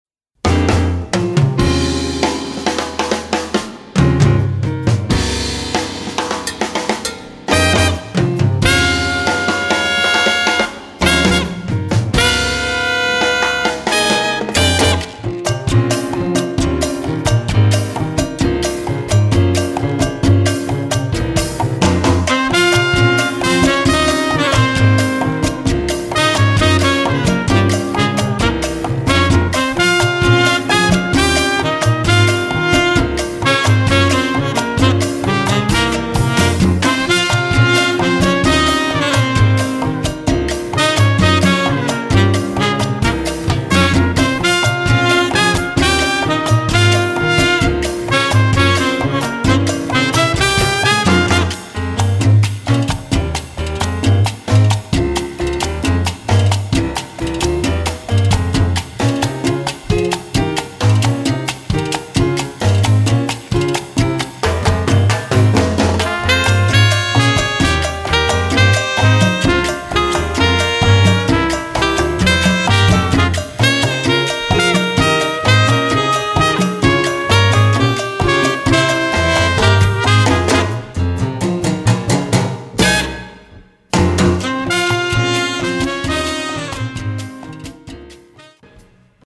Category: combo (sextet)
Style: cha cha
Solos: open
Instrumentation: combo (sextet) trumpet, tenor, rhythm (4)